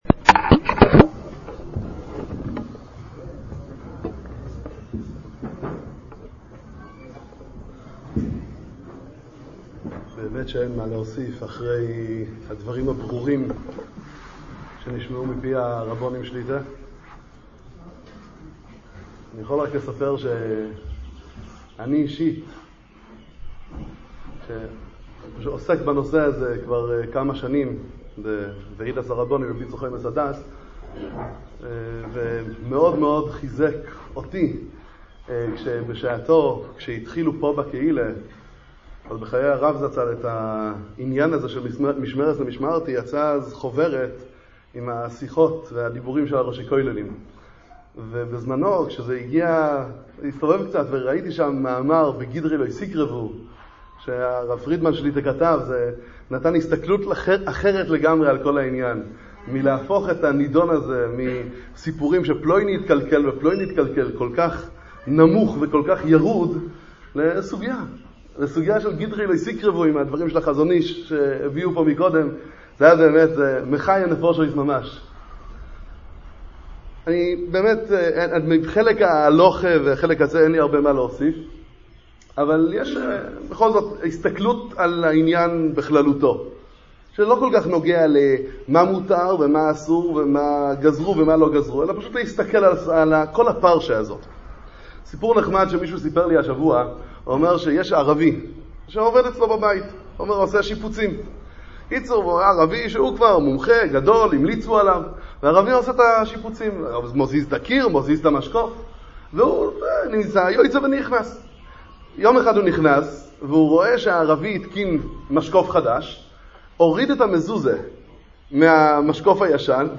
מוצאי שבת זכור תשע"ט.